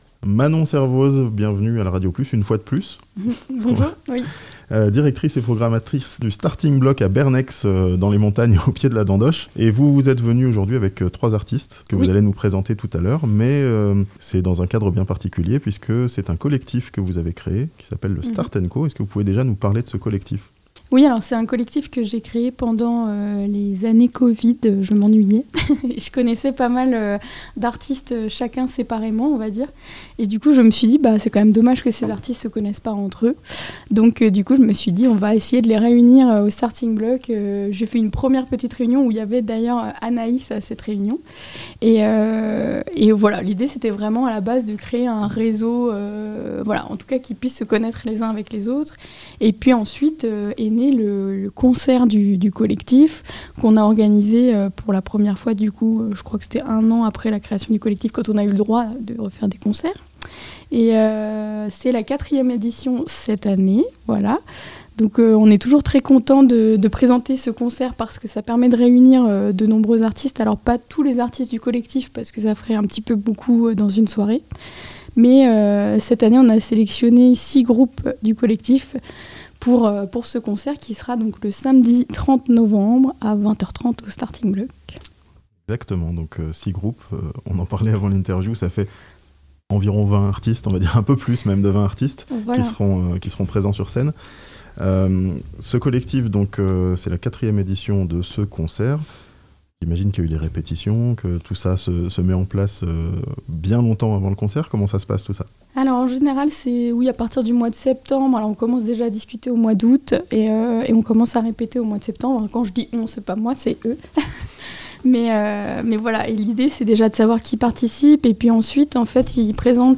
Un collectif d'artistes en concert rock samedi soir à Bernex, dans le Chablais (interview)